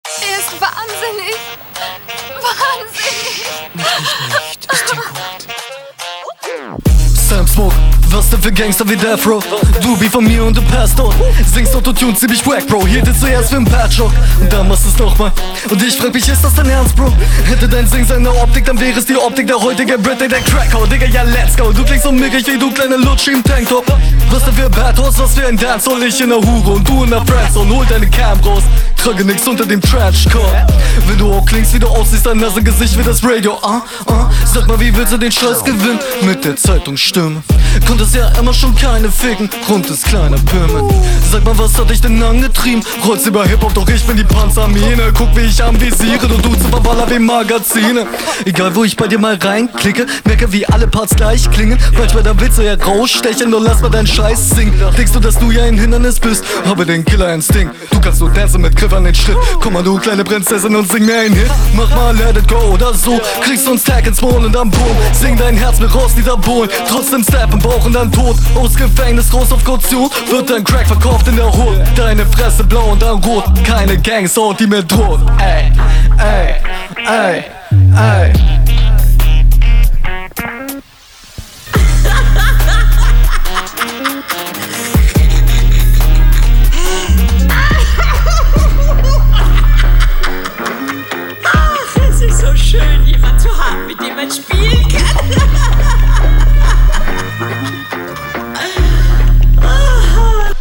Flows sind ultra dope geschrieben, aber manchmal find ich sie nicht so cool recorded wie …